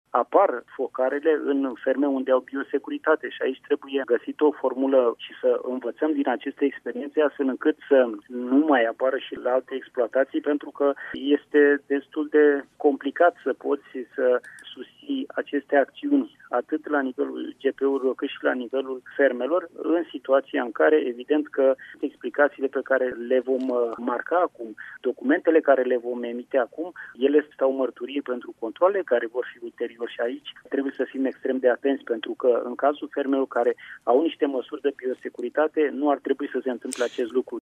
Apariția unor noi focare este, însã, îngrijorãtoare, mai ales în fermele unde s-au luat deja mãsuri de biosecuritate, a spus secretarul de stat în ministerul Agriculturii, Daniel Botãnoiu. El a anunțat, într-o emisiune la Radio România Actualități, cã vor fi fãcute noi controale în acest sens:
21-august-Daniel-Botanoiu.mp3